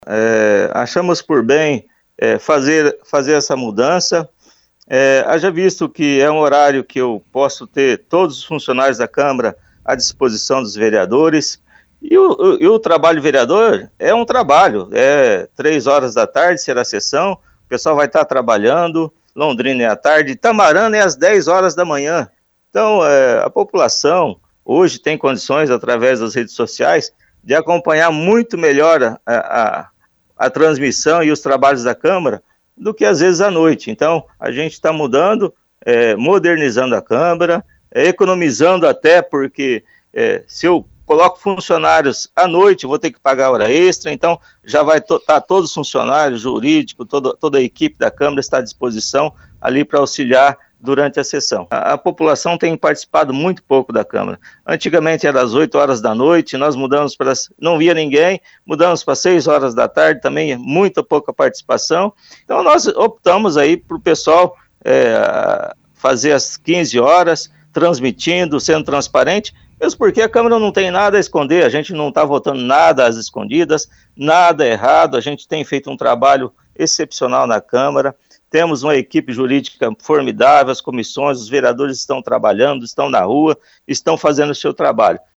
Ouça o presidente da Câmara Reginaldo Silva em entrevista á radio Cobra FM.
SONORA-PRESIDENTE-DA-CAMARA-DE-ROLANDIA.mp3